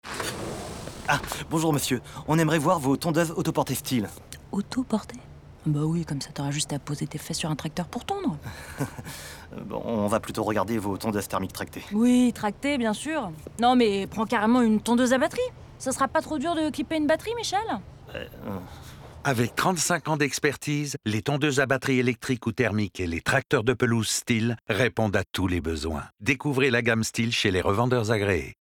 Bandes-son
Voix pub 2